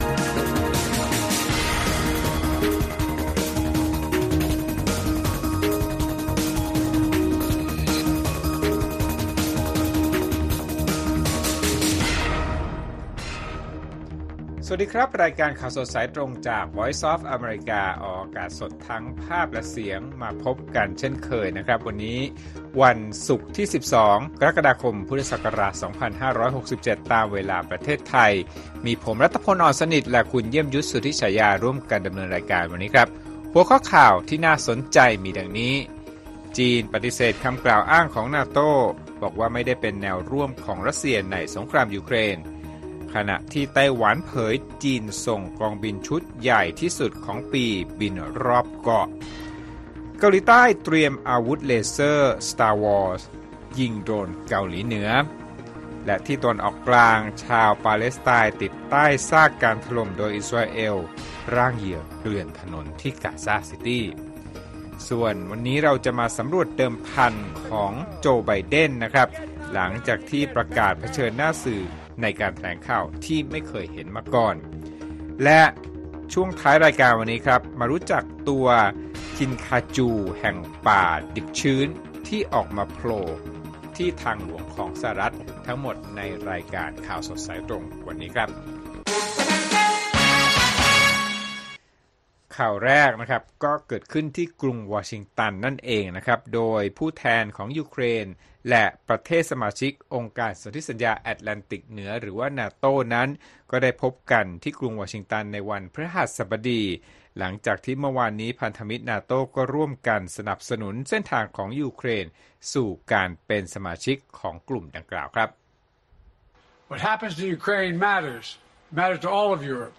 ข่าวสดสายตรงจากวีโอเอ ไทย วันศุกร์ ที่ 12 กรกฎาคม 2567